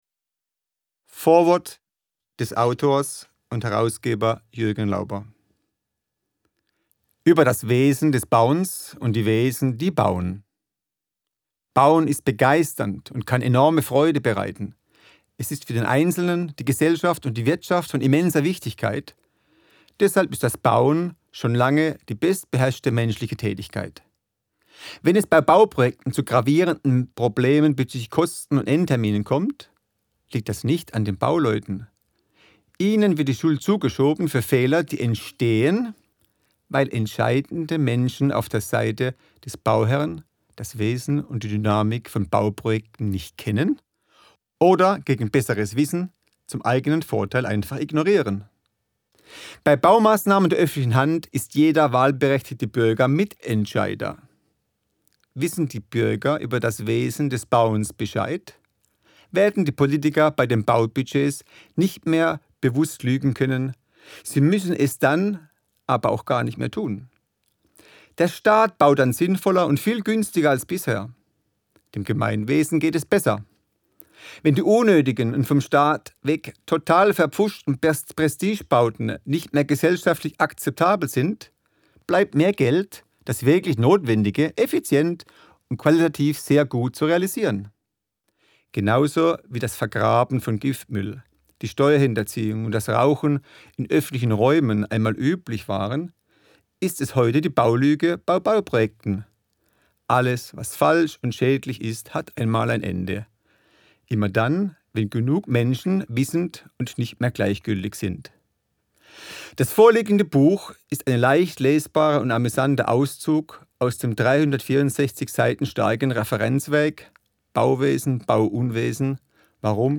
Audiointerview wie er zum Thema kam und das Buch entstand: